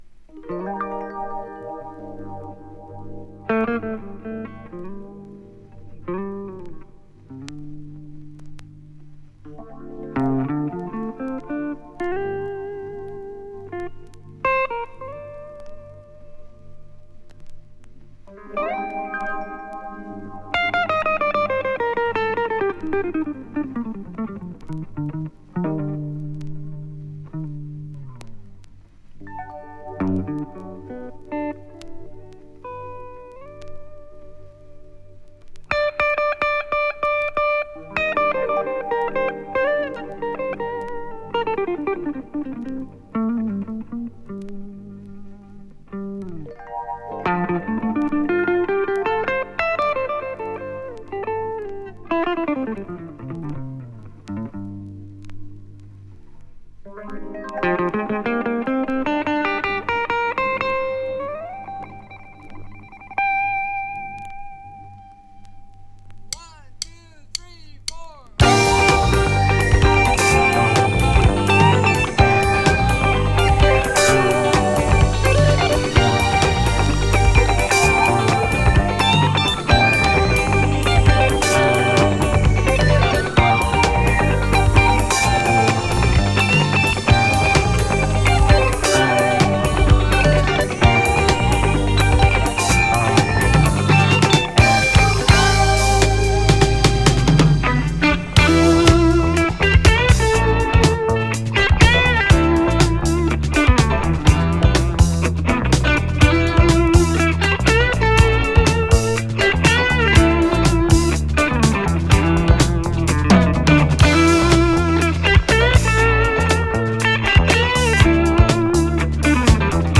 リリカルなギターのテクニックが披露される名作